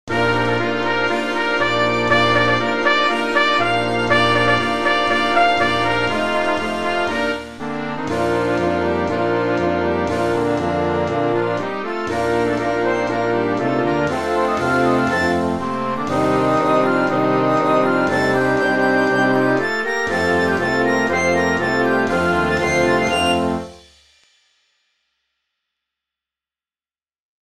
Collection : Harmonie (Marches)
Marche-parade pour harmonie-fanfare,
avec tambours et clairons ad lib.